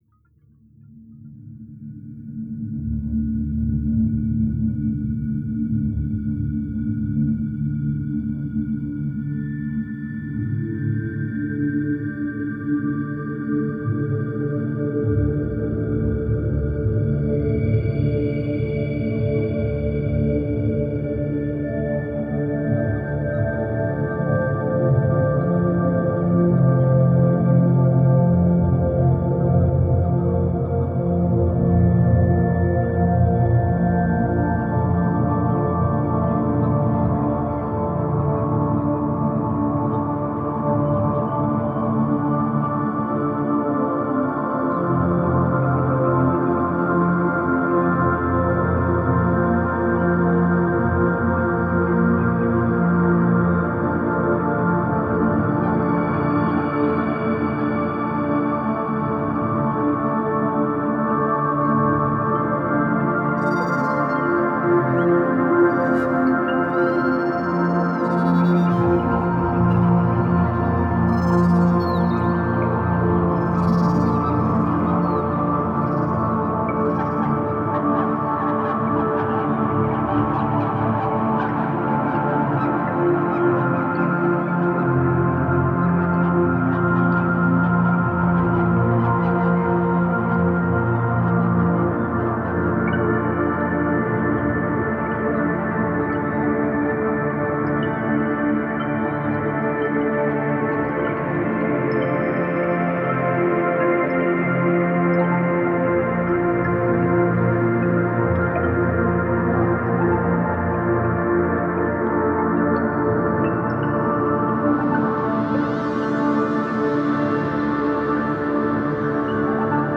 Жанр: Ambient